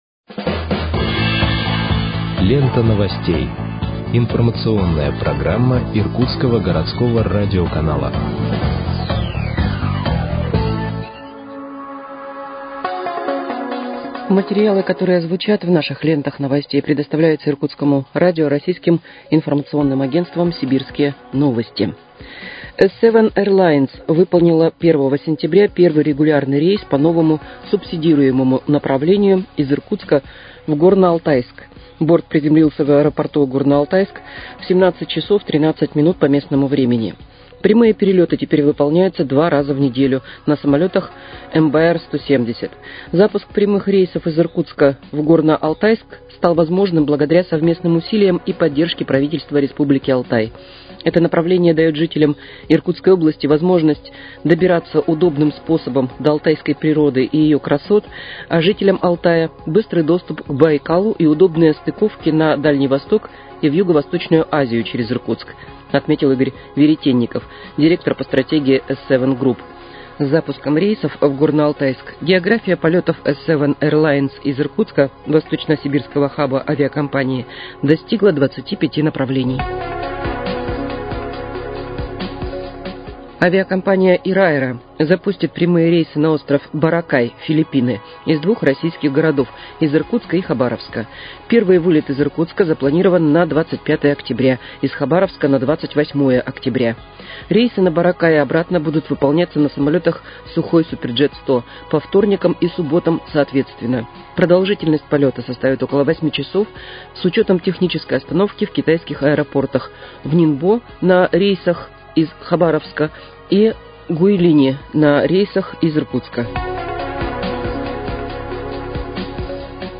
Выпуск новостей в подкастах газеты «Иркутск» от 5.09.2025 № 1